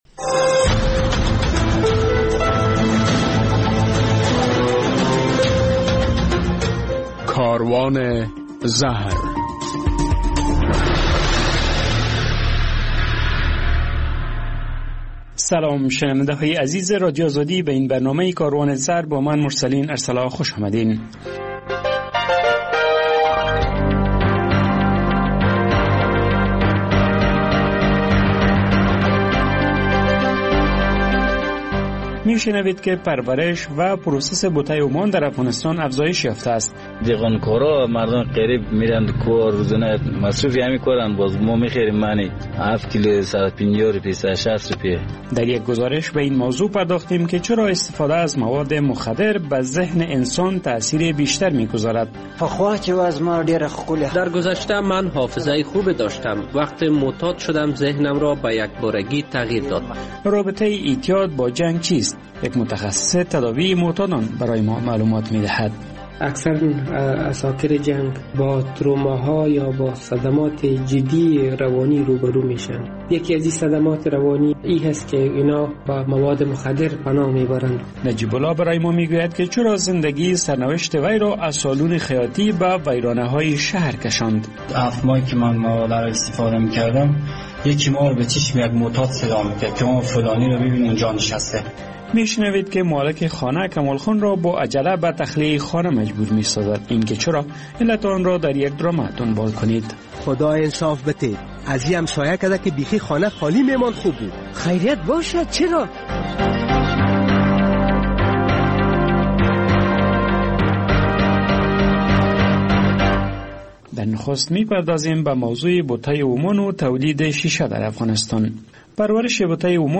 در این برنامه کاروان زهر می‌شنوید که ترویج و پروسس بته ومان از سه ولایت به ده ولایت افغانستان گسترش یافته است، در یک گزارش از تاثیرات ناگوار نشه بر سیستم عصب انسان برای تان می‌گوییم، در مصاحبه با یک متخصص تداوی معتادان از وی پرسیدیم که جنگ و اعتیاد به مواد مخدر باهم چه ارتباط دارند، در ادامه...